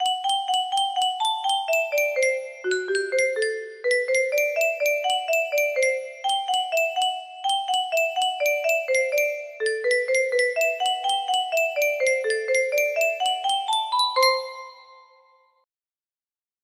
B-day music box melody
Key: Gmaj, (mode: C lydian)
Time:3/4